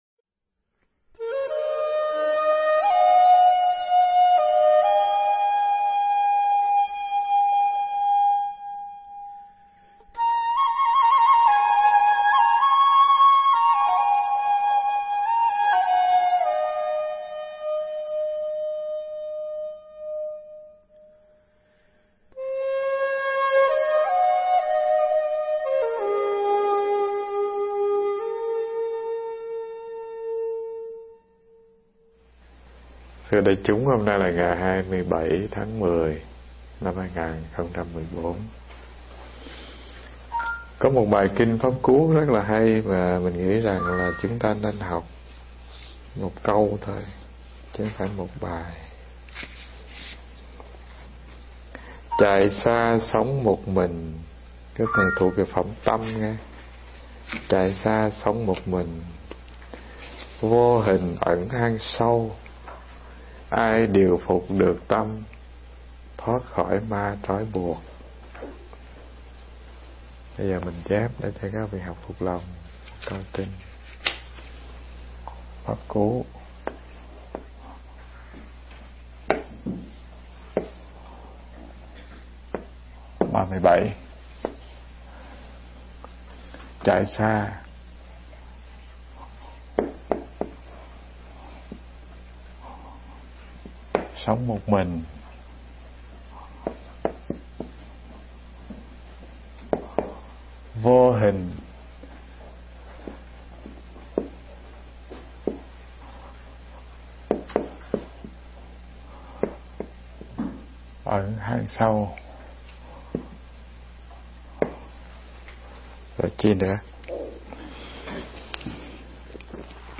Nghe Mp3 thuyết pháp Khéo Điều Phục Tâm Phần 1